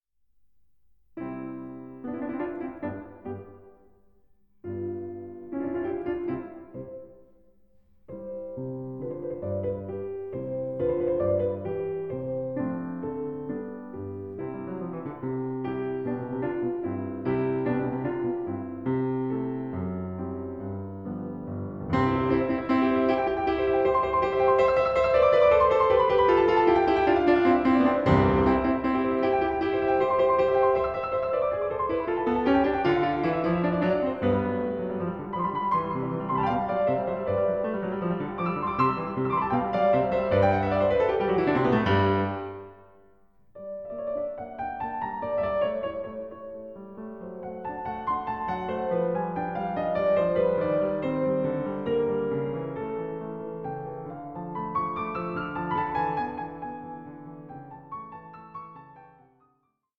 Piano Sonata No. 3 in C, Op. 2, No. 3